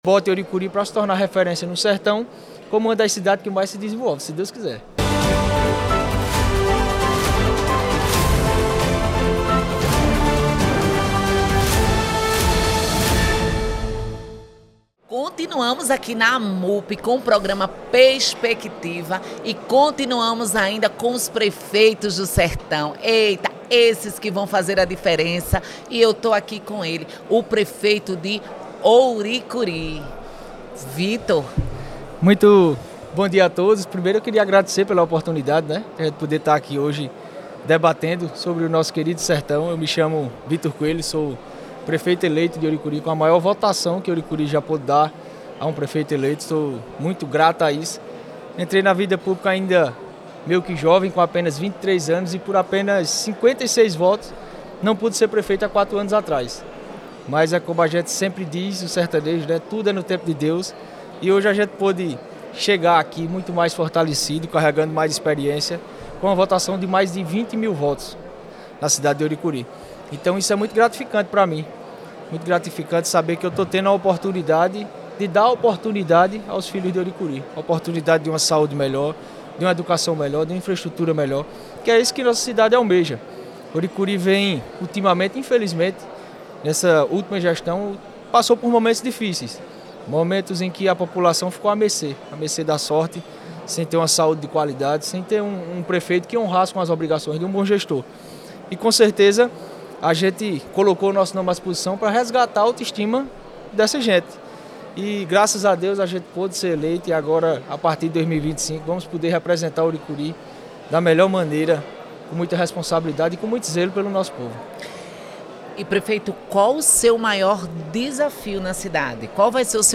Durante entrevista no podcast Perspectiva, da Rede Você, o prefeito Victor Coelho salientou a força do comércio em Ouricuri devido a sua localização e a sua disposição em trabalhar para que ele se fortaleça ainda mais.